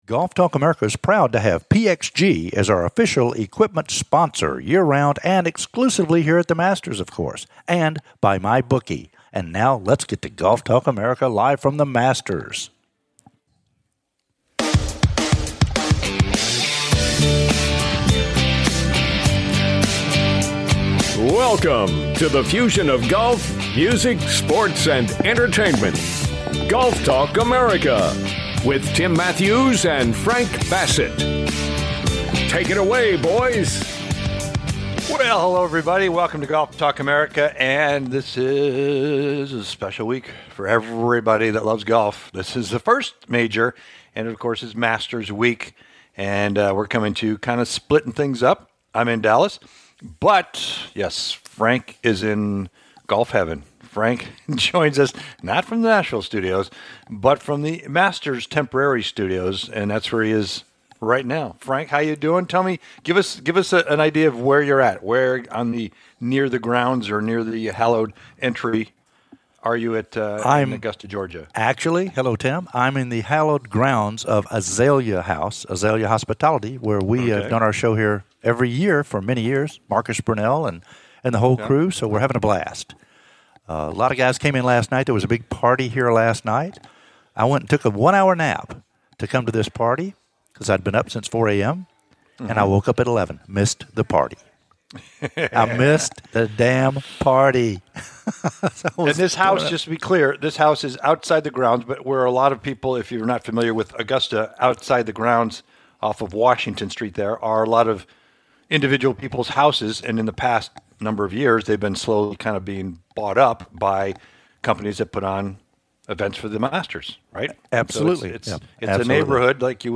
preview the Masters from Augusta